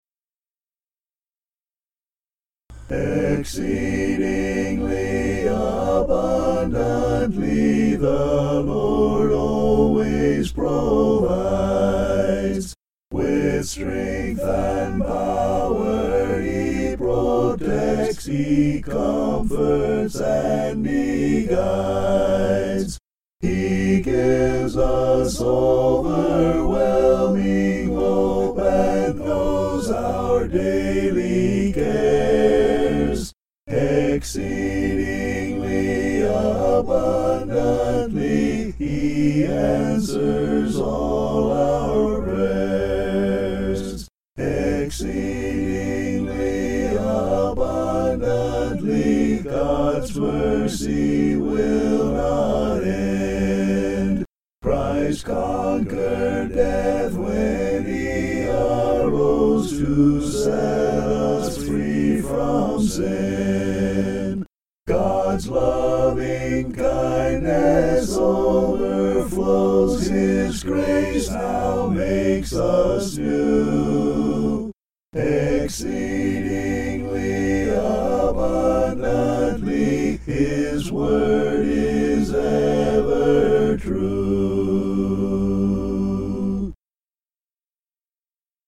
(An original hymn)
vocals